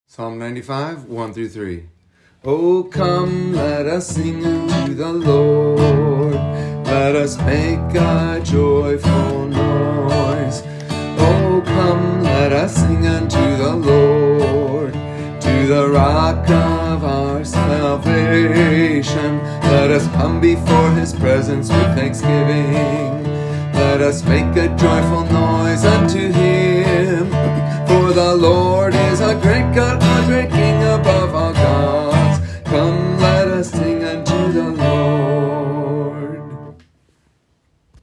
Transpose from D